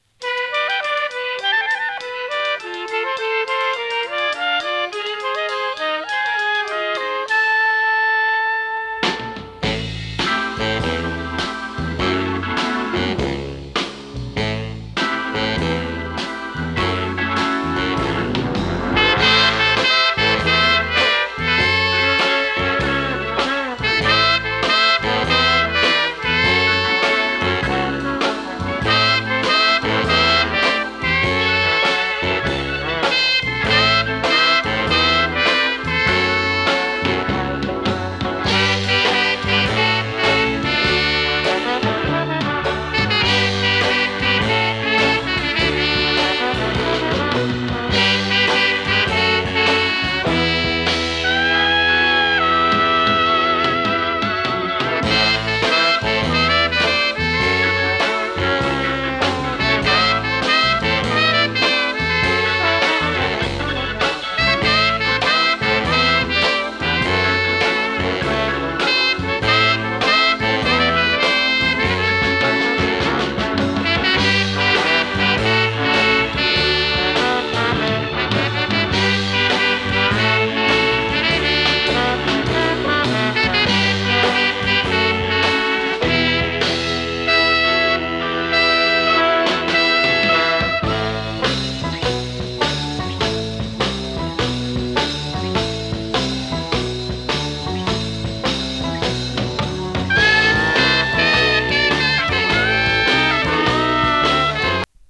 60sポップスの数々をノスタルジックなスウィング・ジャズでカバー！